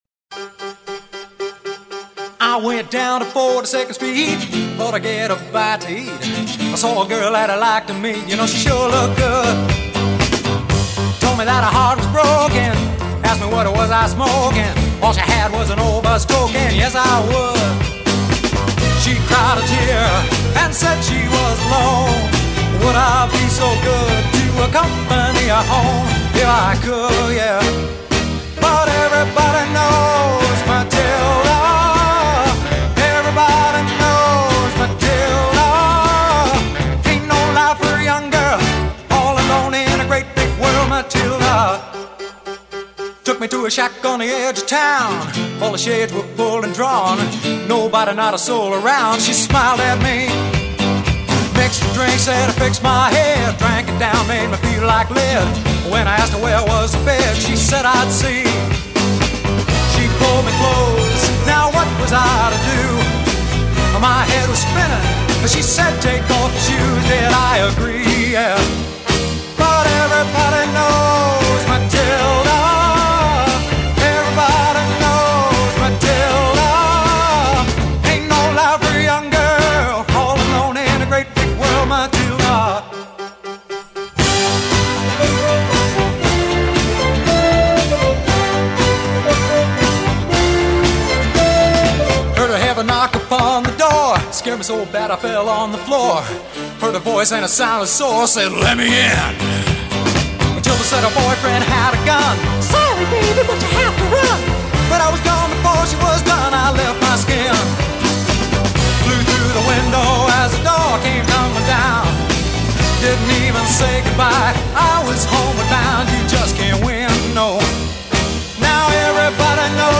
drums
keyboards